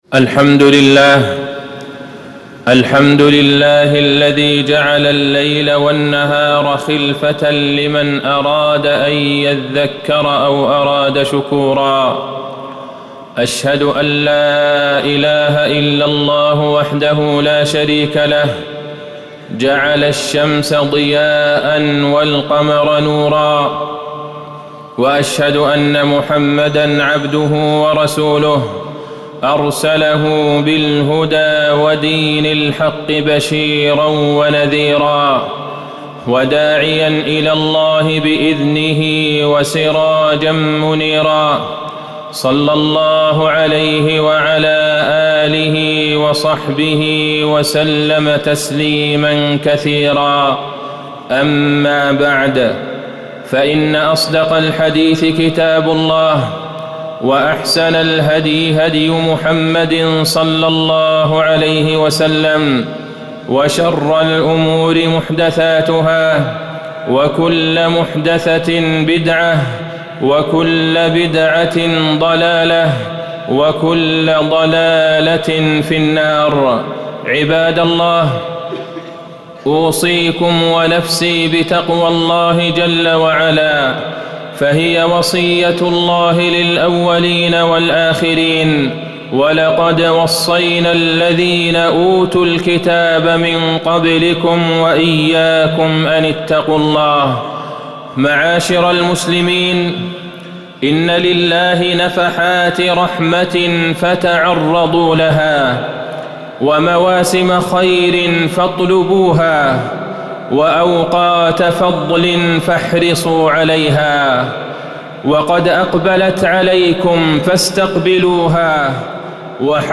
تاريخ النشر ٤ شعبان ١٤٣٩ هـ المكان: المسجد النبوي الشيخ: فضيلة الشيخ د. عبدالله بن عبدالرحمن البعيجان فضيلة الشيخ د. عبدالله بن عبدالرحمن البعيجان شهر شعبان وغفلة الناس